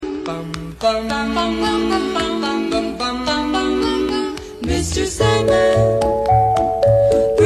Raccoon sound effects free download